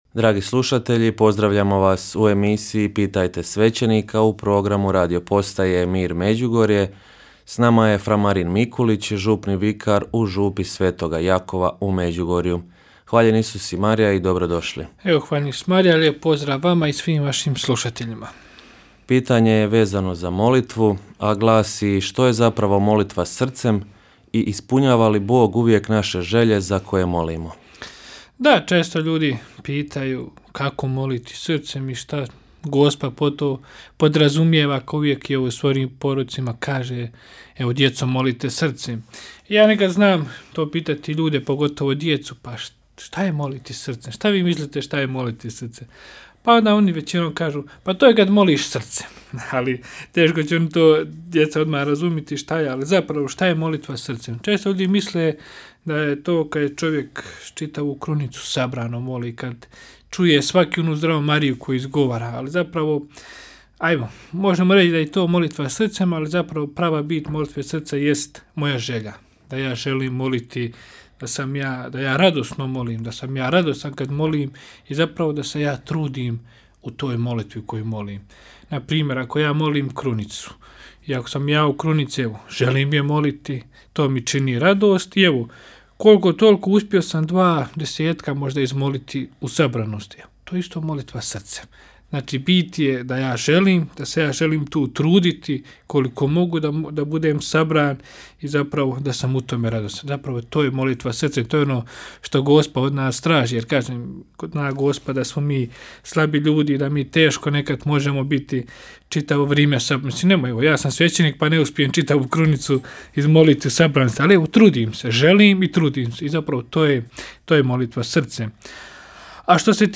Rubrika ‘Pitajte svećenika’ u programu Radiopostaje Mir Međugorje je ponedjeljkom od 8 sati i 20 minuta, te u reprizi ponedjeljkom navečer u 20 sati i 15 minuta. U njoj na pitanja slušatelja odgovaraju svećenici, suradnici Radiopostaje Mir Međugorje.